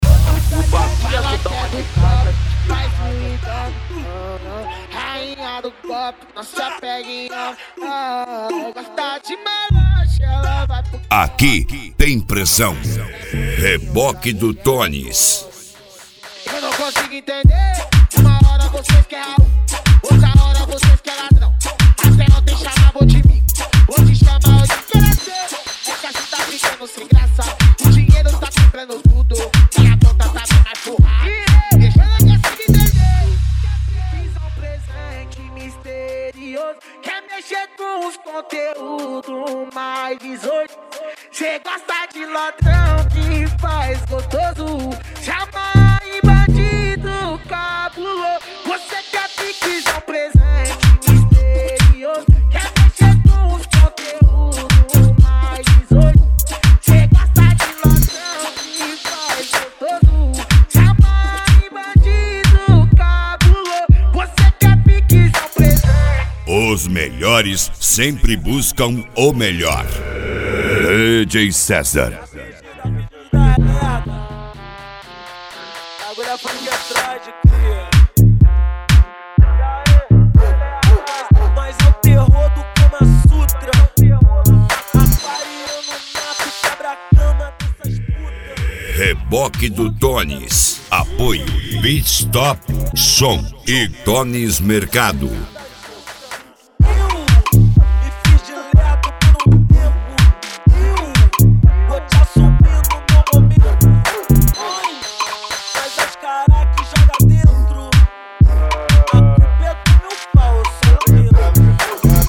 Mega Funk